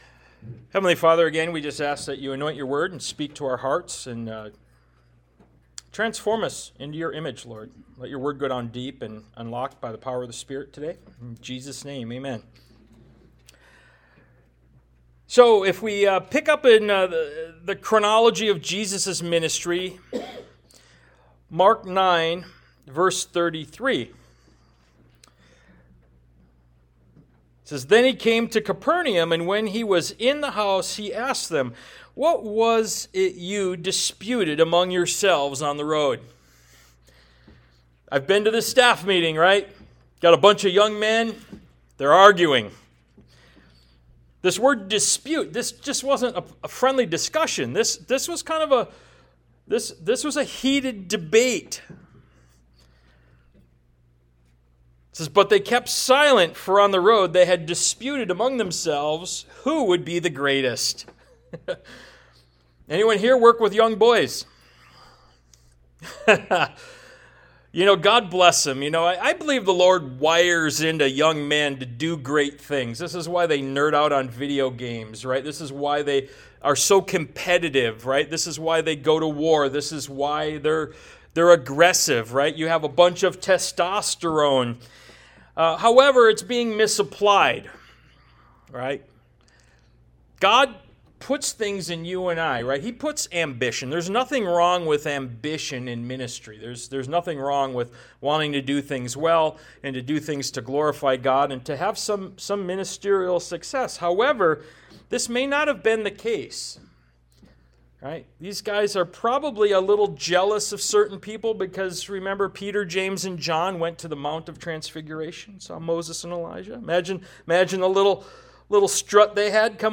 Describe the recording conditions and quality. Ministry of Jesus Service Type: Sunday Morning « “Ephesians 1-14” “The Good Shepherd” Ministry of Jesus Part 47 »